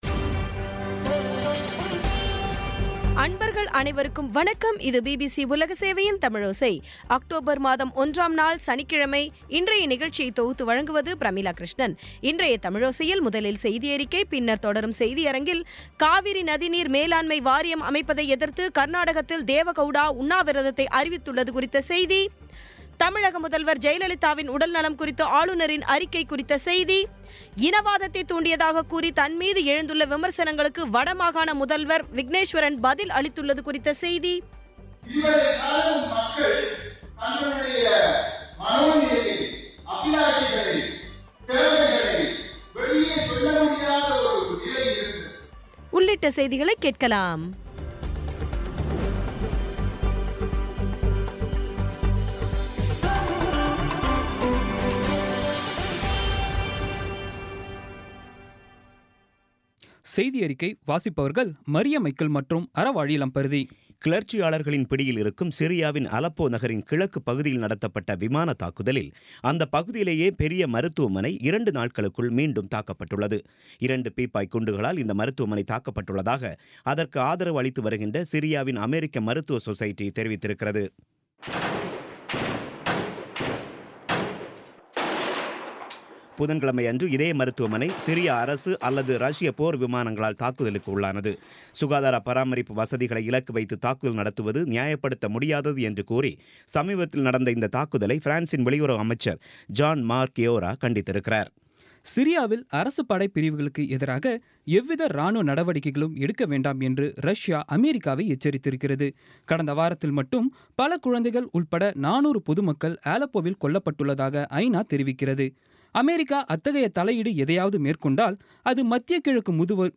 இன்றைய தமிழோசையில், முதலில் செய்தியறிக்கை, பின்னர் தொடரும் செய்தியரங்கத்தில், காவேரி நதி நீர் மேலாண்மை வாரியம் அமைப்பதை எதிர்த்து கர்நாடகத்தில் தேவ கவுடா உண்ணாவிரதத்தை அறிவித்தது குறித்த செய்தி தமிழக முதல்வர் ஜெயலலிதாவின் உடல் நலம் குறித்து ஆளுநரின் அறிக்கை குறித்த செய்தி இனவாதத்தைத் தூண்டியதாகக் கூறி தன் மீது எழுந்துள்ள விமர்சனங்களுக்கு வட மாகாண முதல்வர் விக்னேஸ்வரன் பதில் அளித்தது குறித்த செய்தி